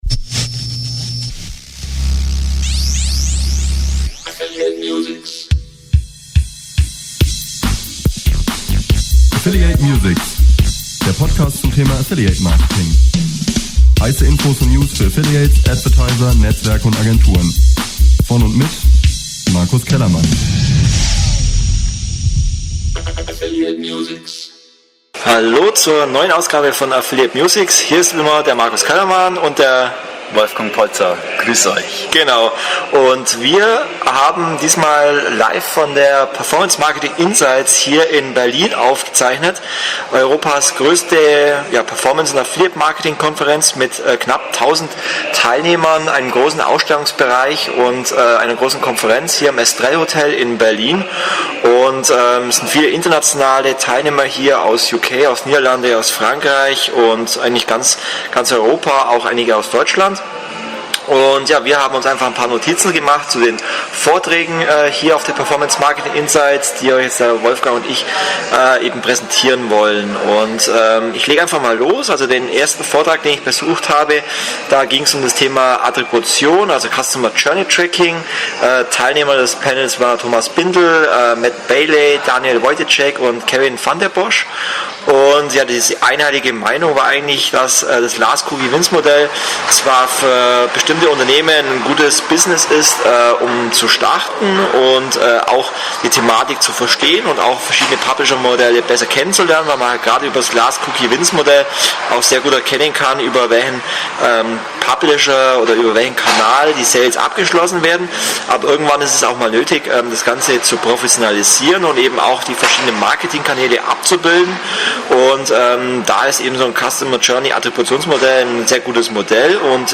live von der Performance Marketing Insights, Europas größter Konferenz zum Thema Performance Marketing. Diese fand vom 24. bis 25. Juni im Berliner Estrel-Hotel statt....